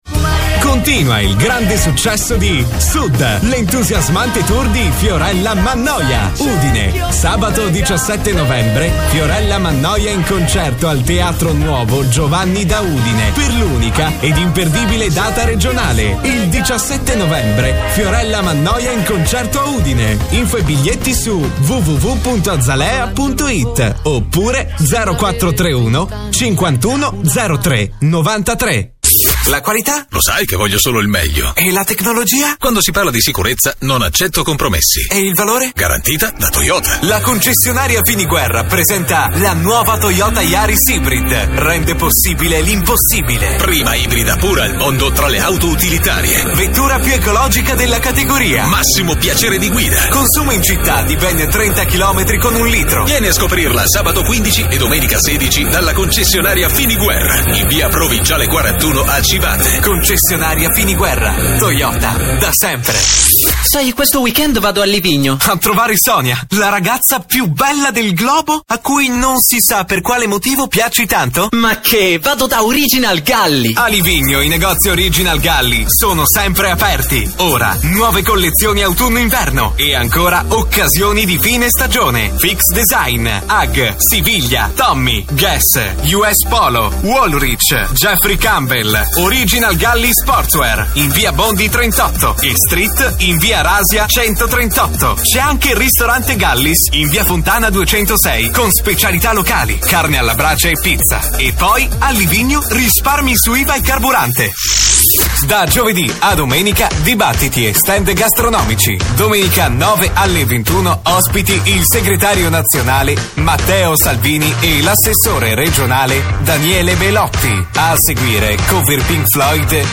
Speaker Conduttore e doppiatore pubblicitario per diverse realtà.
sizilianisch
Sprechprobe: Werbung (Muttersprache):